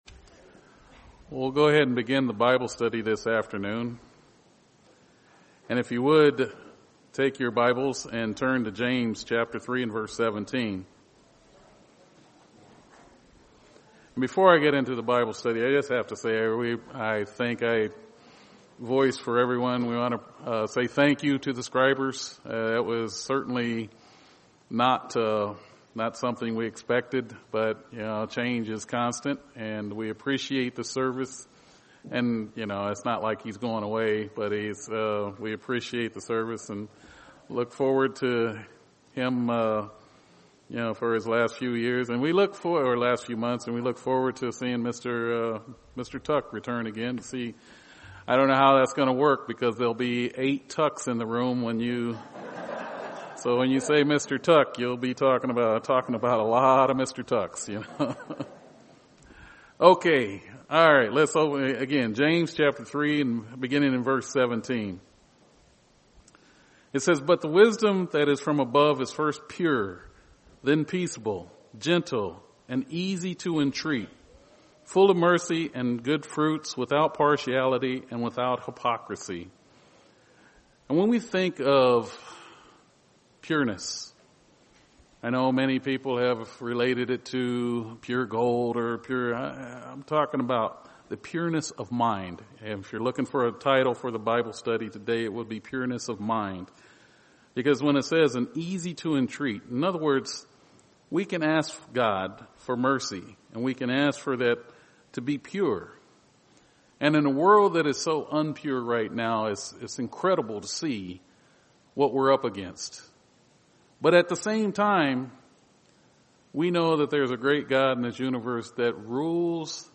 A Bible Study: We will explore the scriptures to understand more of what God says are pure thoughts and why we must seek them.
Given in Phoenix East, AZ